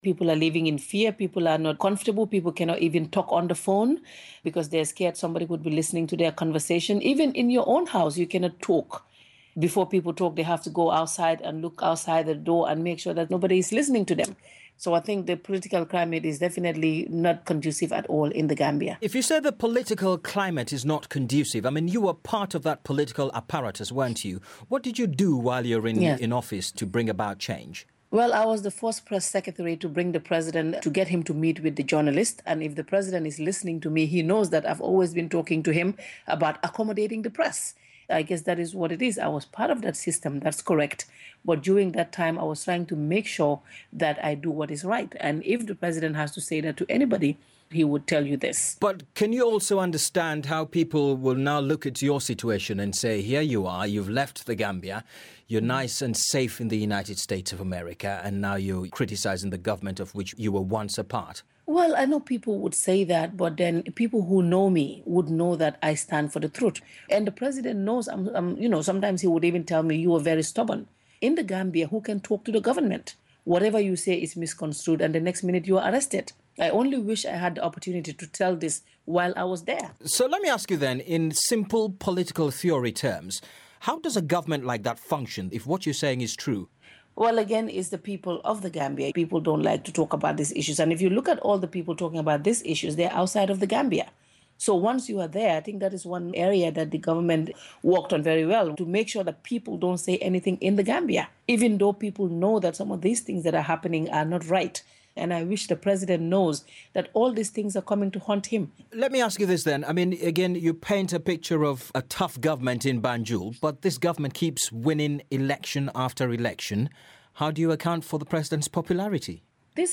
Exclusive Interview with Fatou Camara